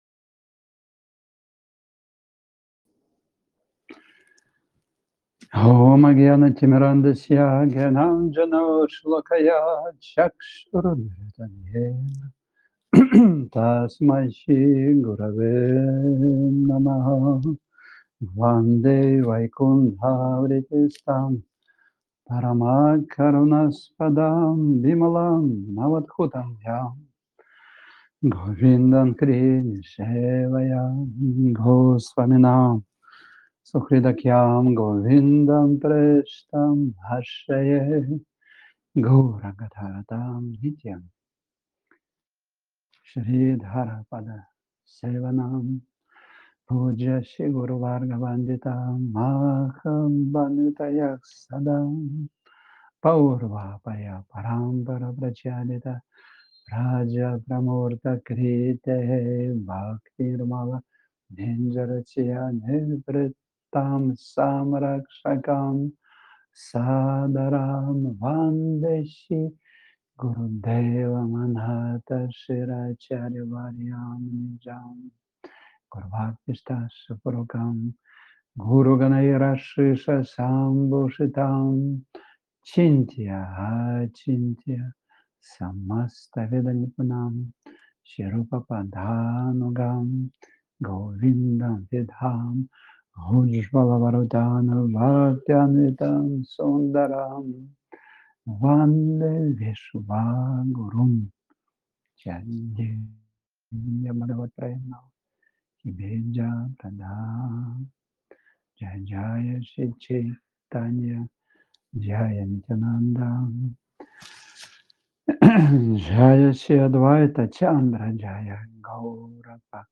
Вриндаван Дхама, Индия